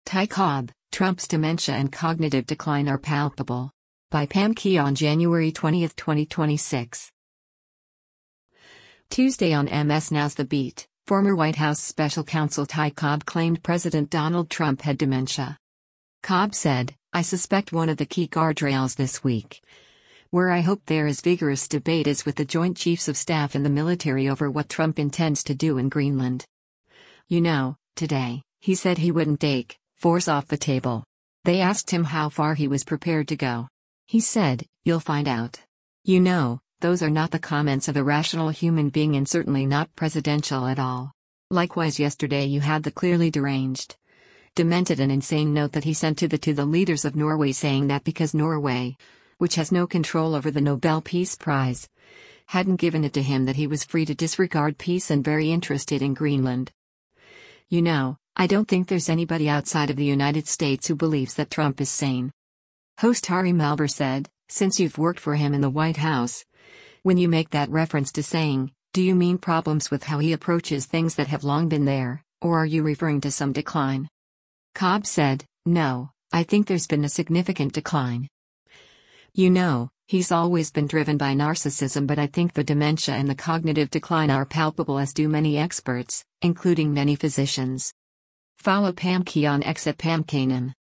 Tuesday on MS NOW’s “The Beat,” former White House special counsel Ty Cobb claimed President Donald Trump had “dementia.”